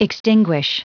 Prononciation du mot extinguish en anglais (fichier audio)
Prononciation du mot : extinguish